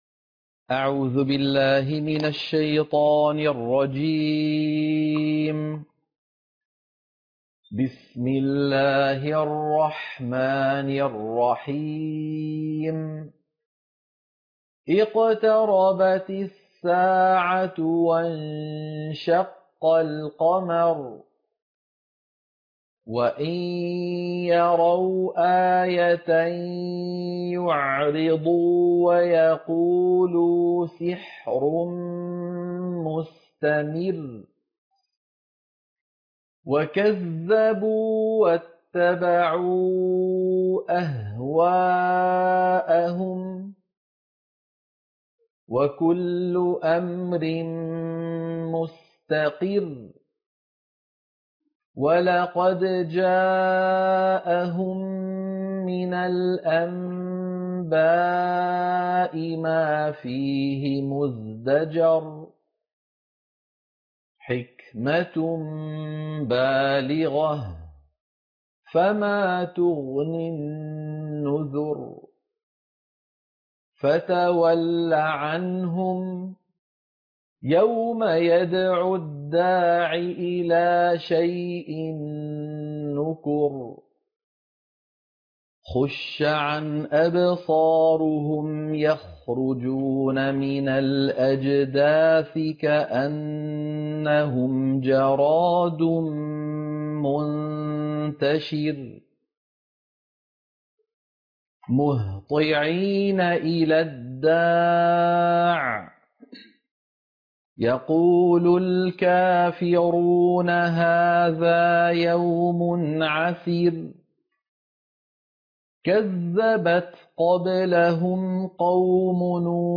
سورة القمر - القراءة المنهجية